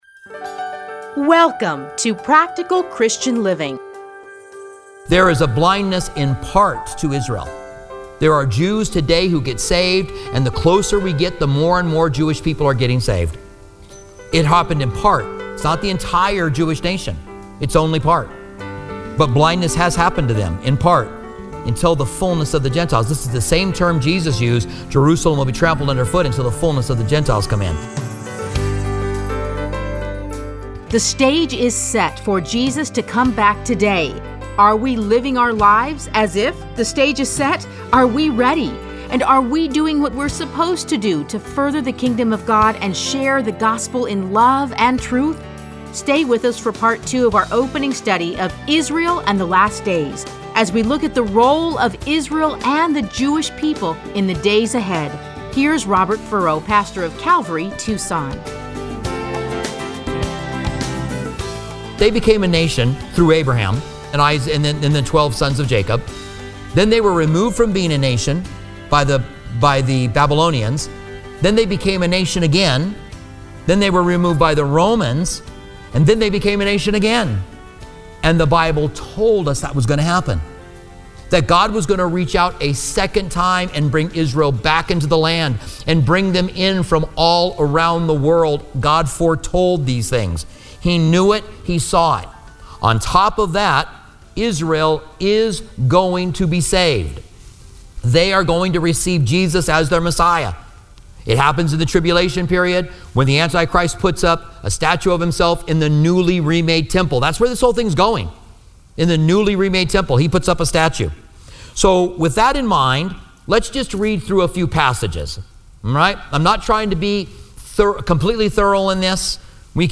Listen to a teaching from selected passages.